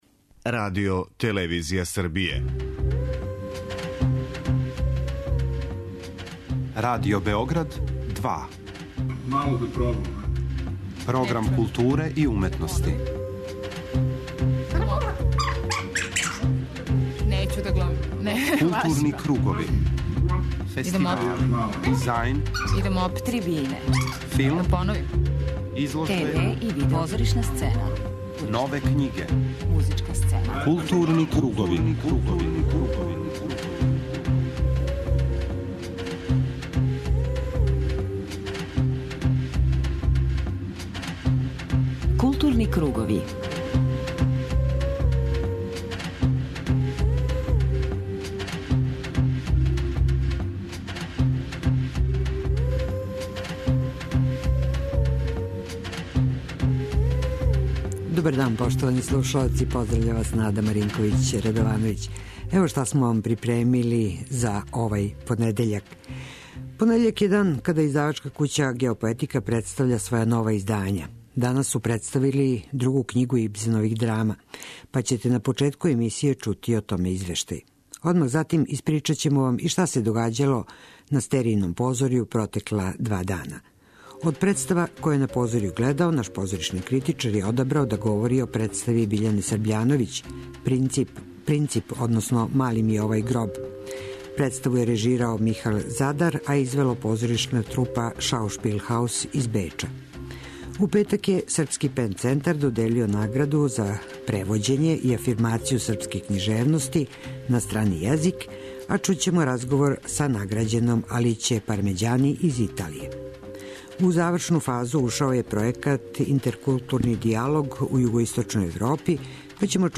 преузми : 54.90 MB Културни кругови Autor: Група аутора Централна културно-уметничка емисија Радио Београда 2.